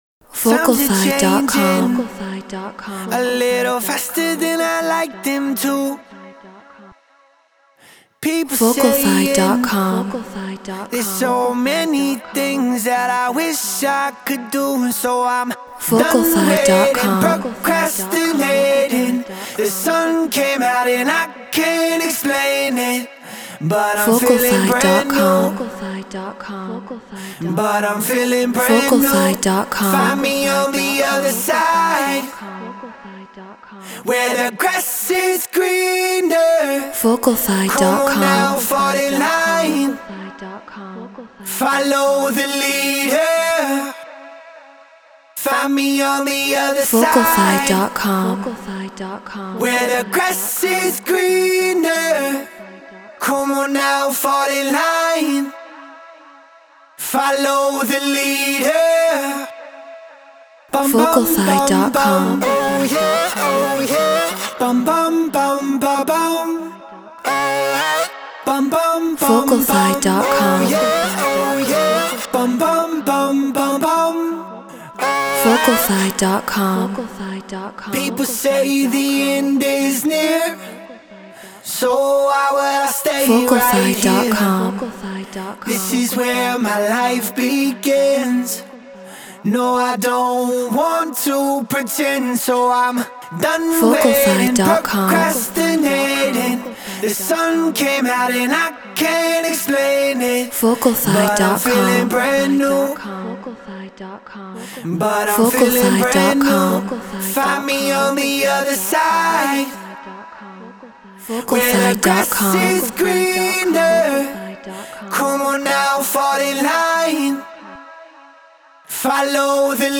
Get Royalty Free Vocals.
Prime vocal.